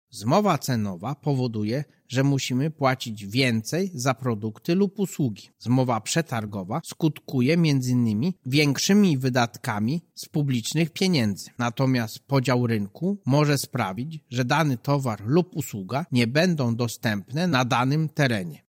Więcej o zmowach mówi Marek Niechciał, prezes Urzędu Ochrony Konkurencji i Konsumentów.